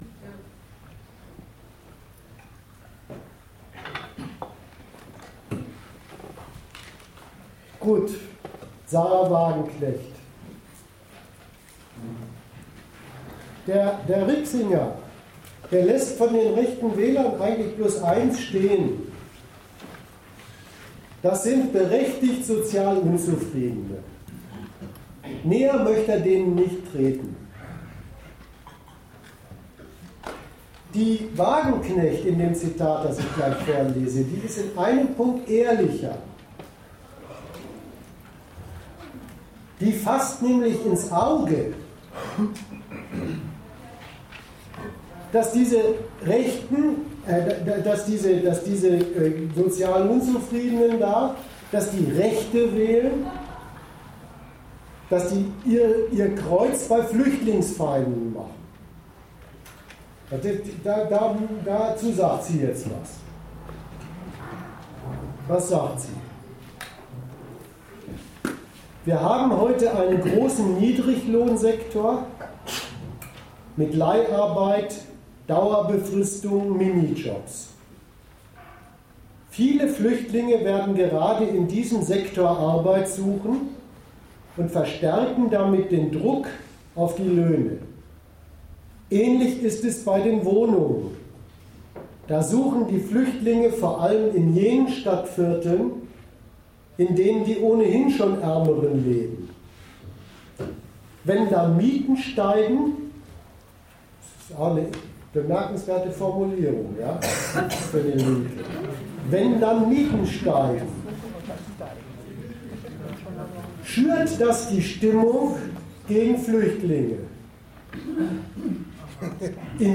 Aufgrund eines technischen Fehlers fehlt das Ende des Vortrages.